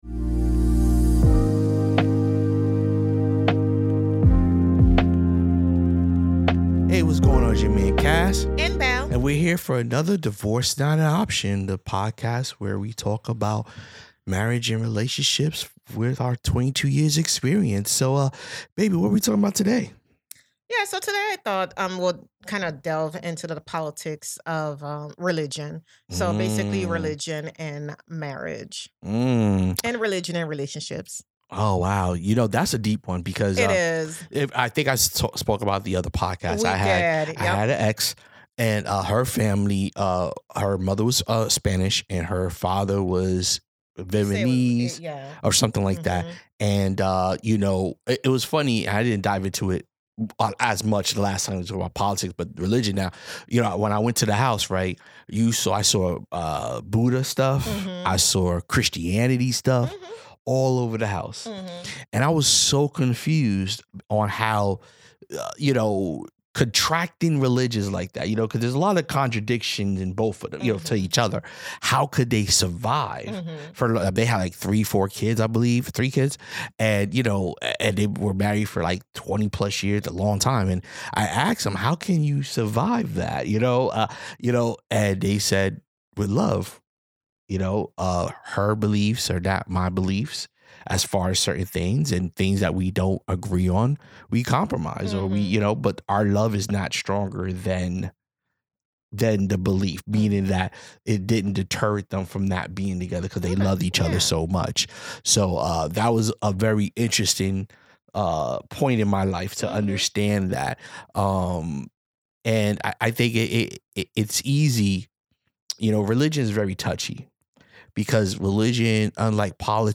heartfelt conversation